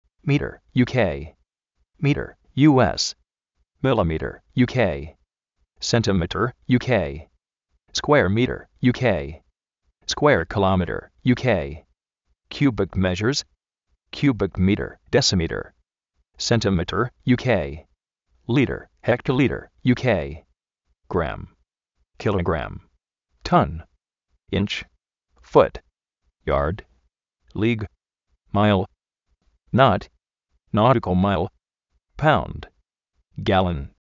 mírer, mírer
skuéar mírer
kiúbic méshers
iárd, lí:g, máil